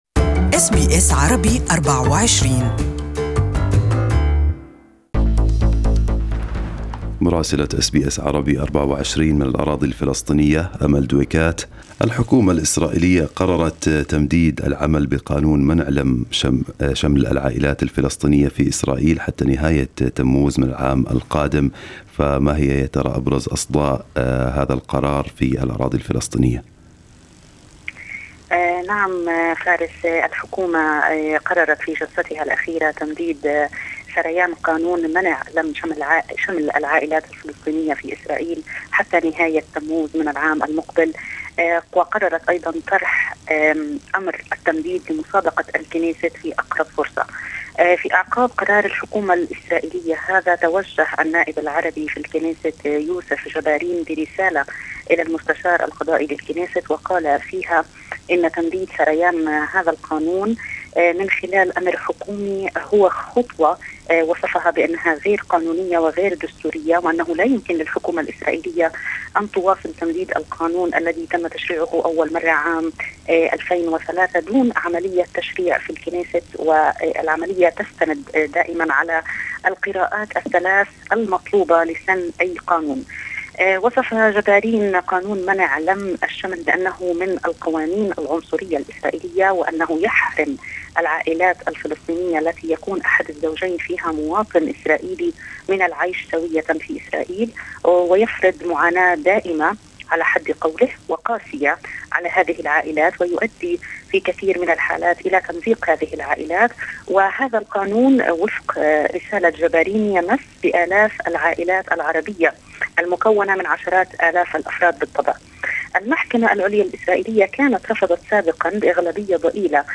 Our correspondent in Ramallah has the details